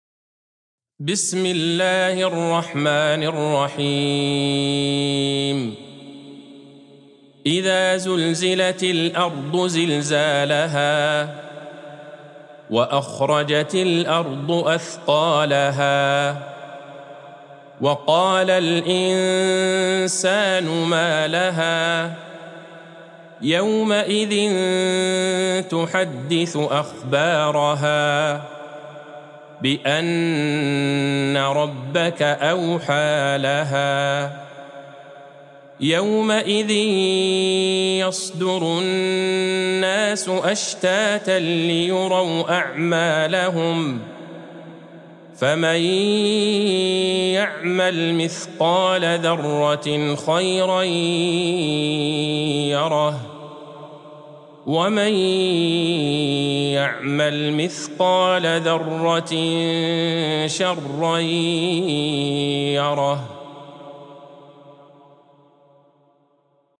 سورة الزلزلة Surat Az-Zalzalah | مصحف المقارئ القرآنية > الختمة المرتلة ( مصحف المقارئ القرآنية) للشيخ عبدالله البعيجان > المصحف - تلاوات الحرمين